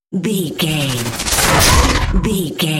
Whoosh to hit sci fi
Sound Effects
dark
futuristic
intense
woosh to hit